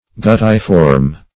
Search Result for " guttiform" : The Collaborative International Dictionary of English v.0.48: Guttiform \Gut"ti*form\, a. [L. gutta a drop + -form.] Drop-shaped, as a spot of color.